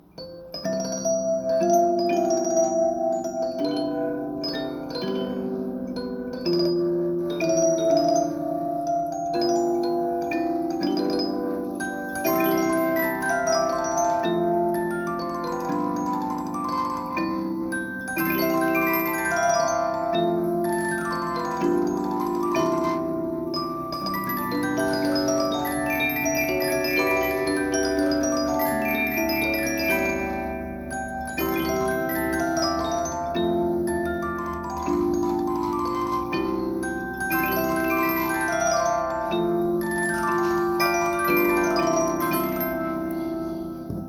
Six Tune Swiss Music Box - Tremolo Zither for sale
(If you don't understand why in general the fewer tunes, the better, please consult our Antique Music Box Tutorial).There is no damage to the comb, no broken teeth, no broken tips of teeth, no damper squeak, no damper buzz as you almost always find on a box of this type after over 100 years of usage.
This six tune Swiss music box, playing off a 13" cylinder, is something special.
Six-Tune-Swiss-Box.mp3